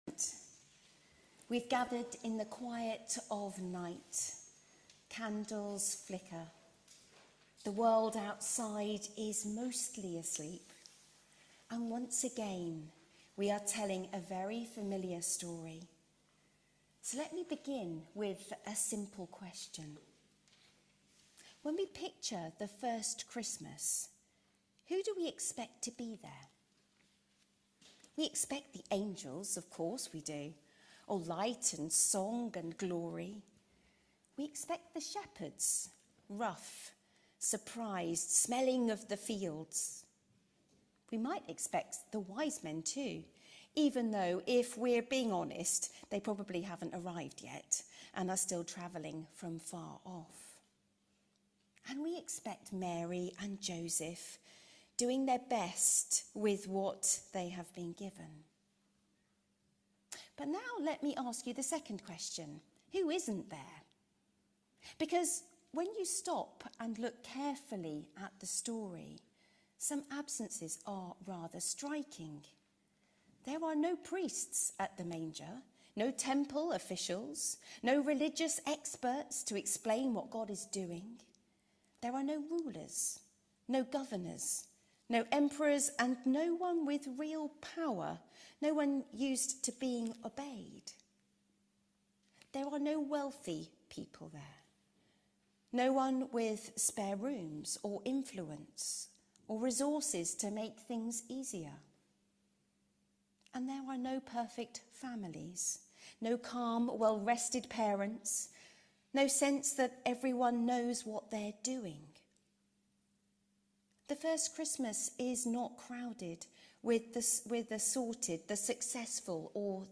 HomeSermonsCome as you are. Come and…